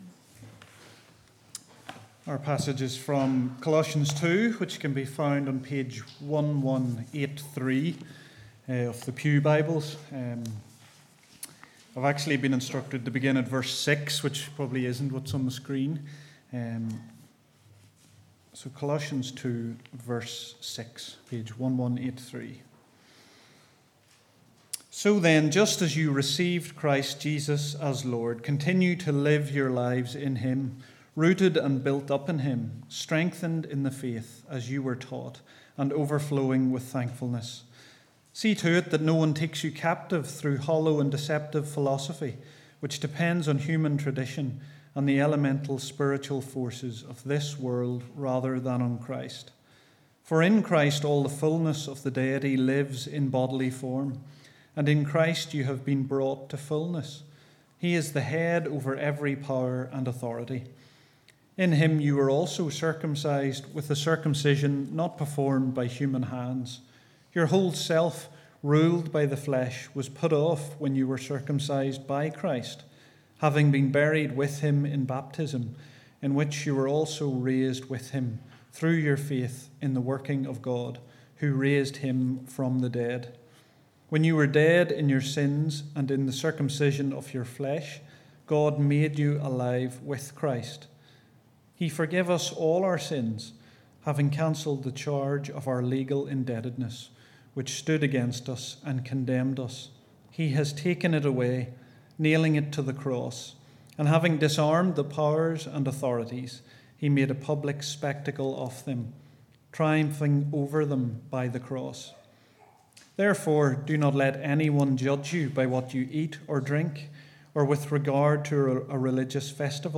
17-october-serrmon.mp3